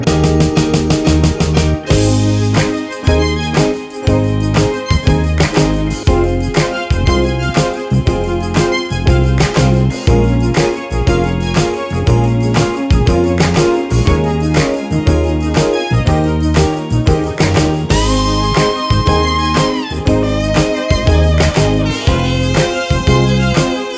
Minus Saxophone Soundtracks 3:24 Buy £1.50